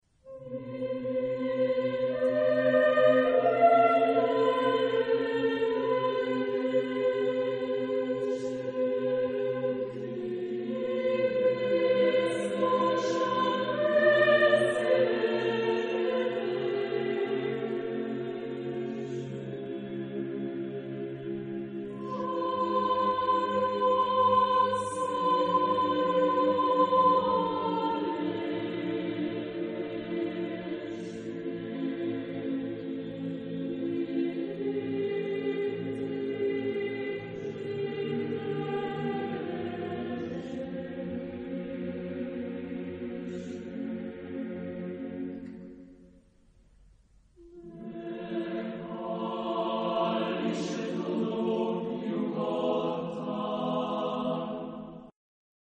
Genre-Style-Forme : Profane ; contemporain ; Lyrique ; Chœur
Type de choeur : SATB  (4 voix mixtes )
Tonalité : si bémol mineur